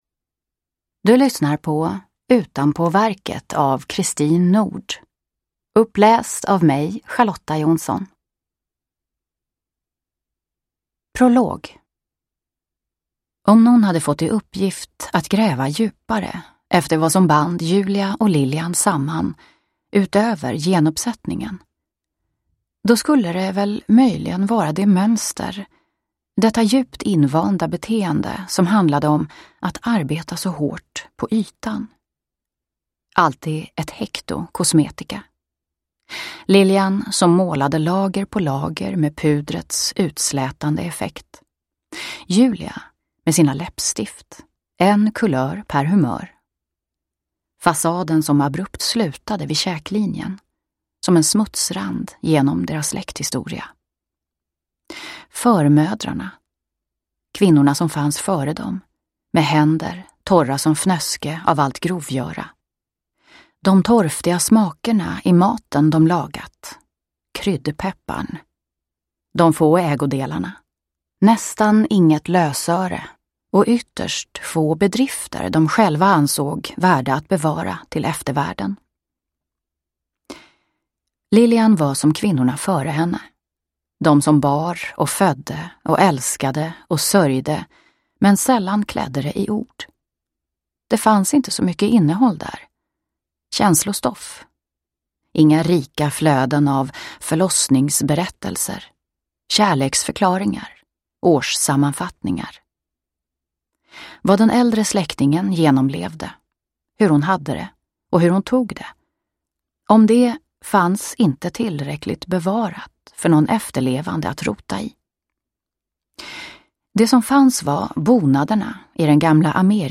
Utanpåverket – Ljudbok – Laddas ner